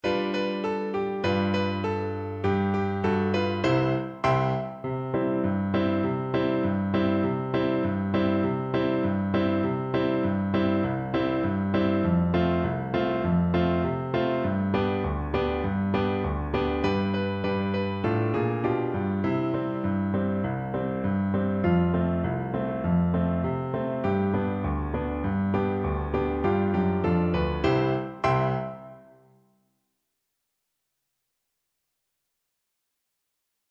Play (or use space bar on your keyboard) Pause Music Playalong - Piano Accompaniment Playalong Band Accompaniment not yet available transpose reset tempo print settings full screen
French Horn
Raucous, two in a bar =c.100
2/2 (View more 2/2 Music)
E4-D5
C major (Sounding Pitch) G major (French Horn in F) (View more C major Music for French Horn )
Traditional (View more Traditional French Horn Music)